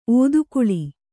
♪ ōdukuḷi